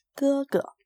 Gē ge
グァグァ